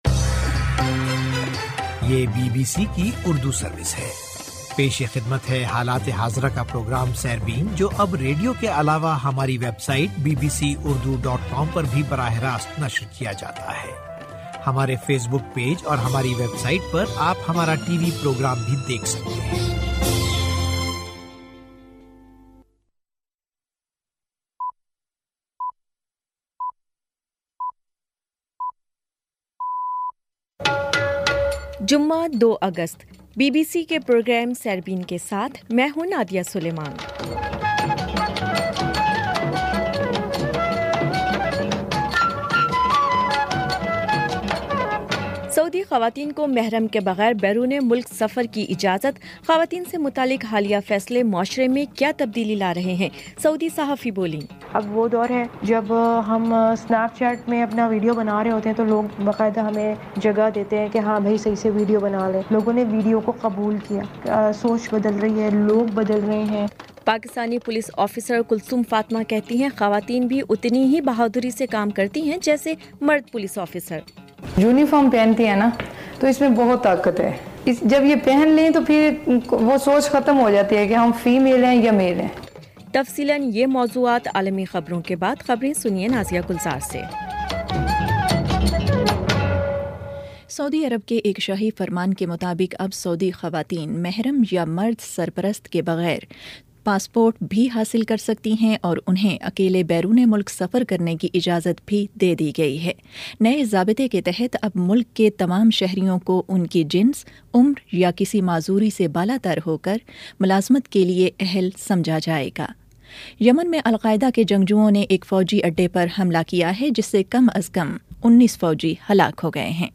جمعہ 02 اگست کا سیربین ریڈیو پروگرام